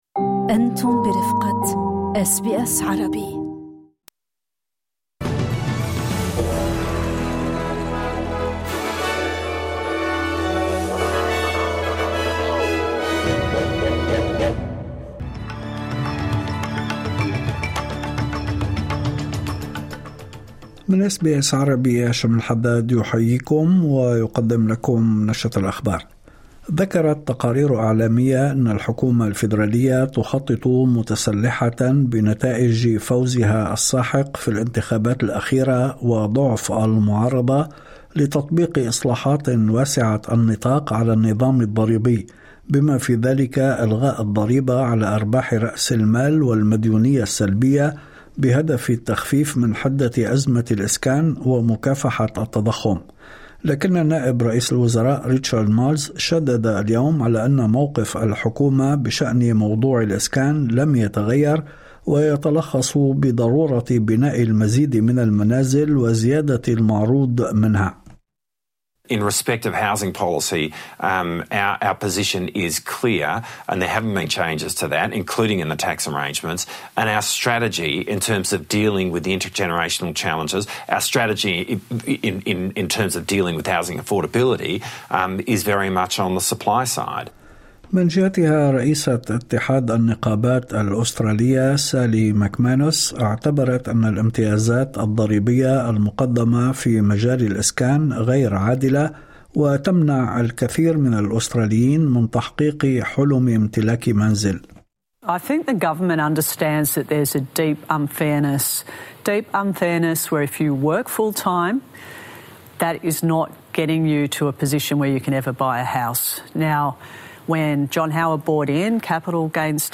نشرة أخبار الظهيرة 05/02/2026